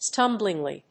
アクセント・音節stúm・bling・ly